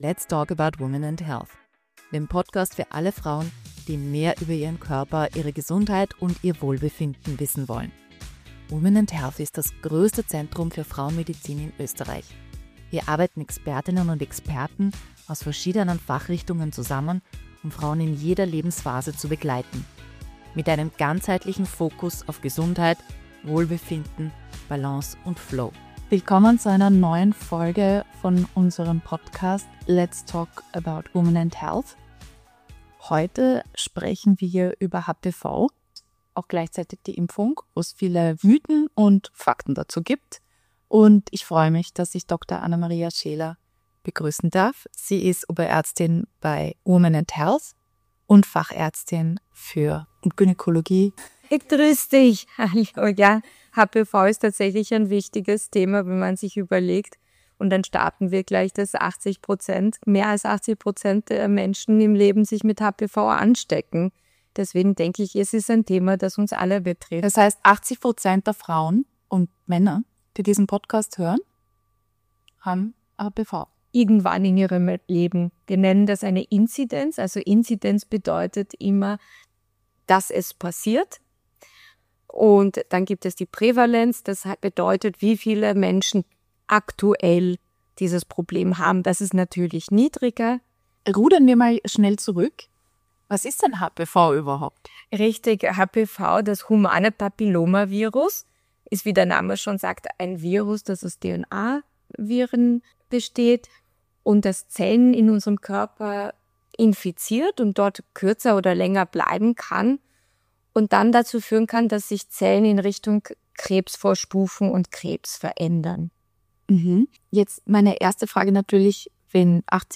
Eine ehrliche, informative und entstigmatisierende Unterhaltung – für alle, die mehr über ihren Körper und ihre Gesundheit wissen wollen.